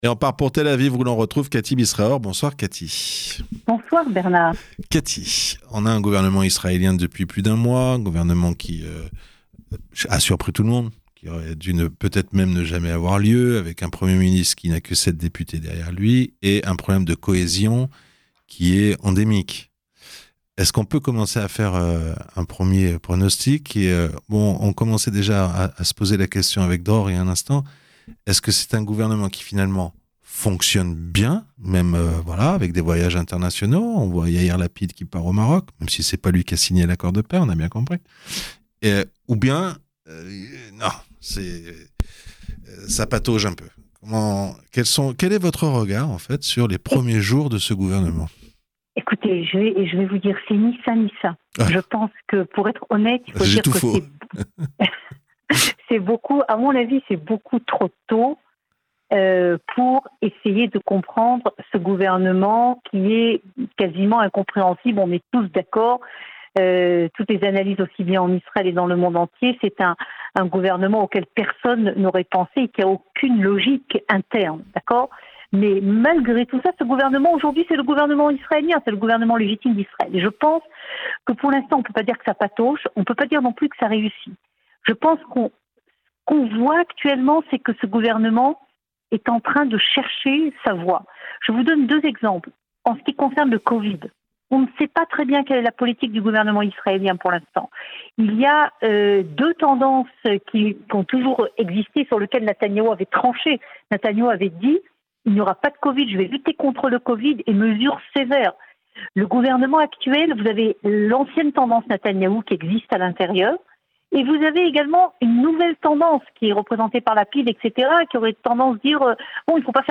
Analyse à Jérusalem